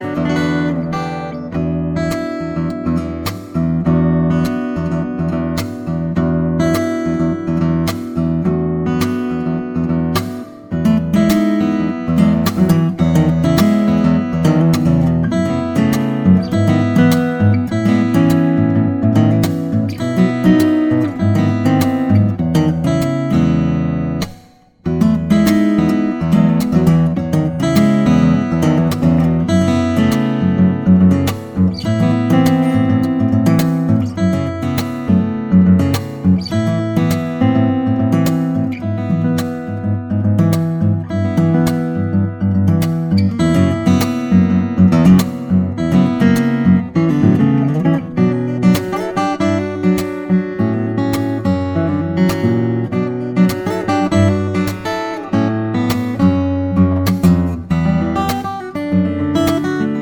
• Sachgebiet: Liedermacher